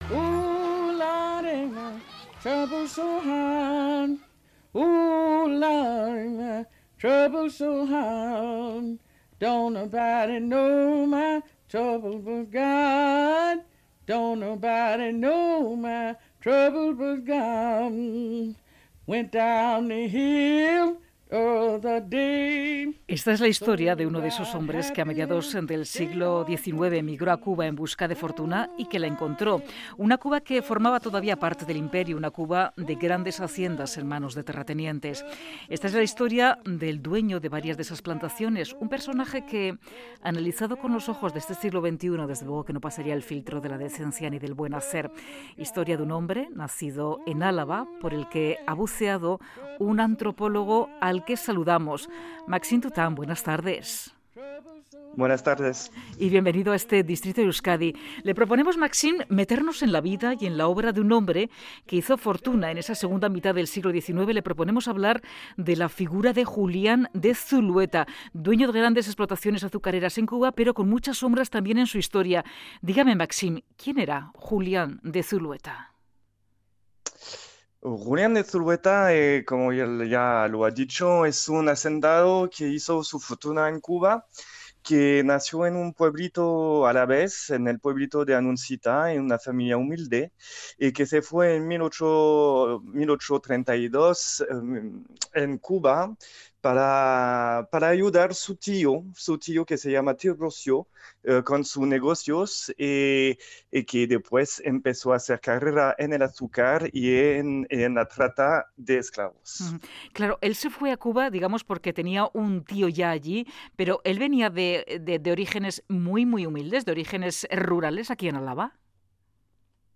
Radio Euskadi ENTREVISTAS